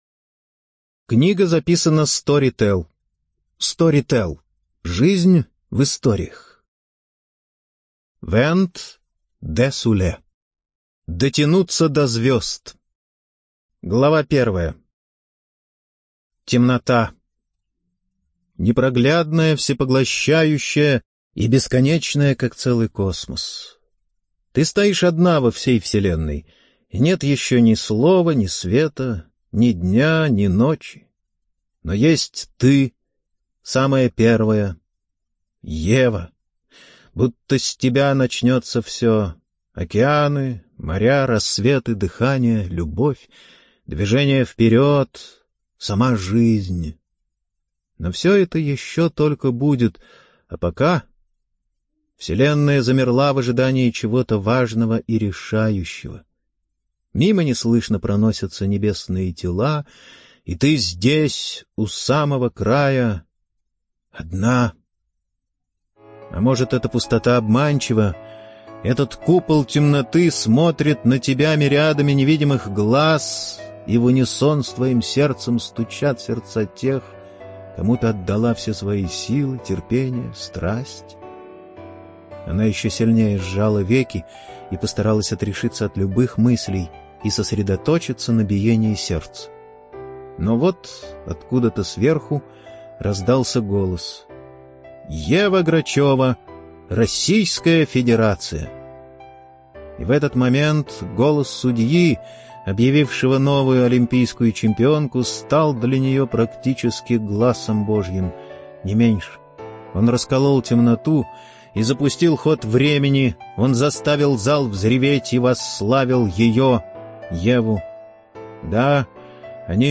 ЖанрСовременная проза
Студия звукозаписиStorytel